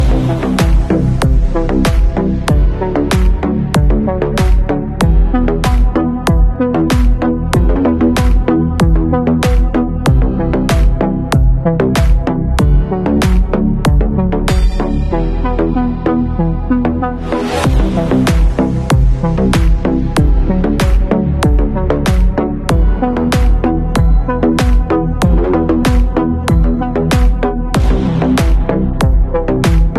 压迫感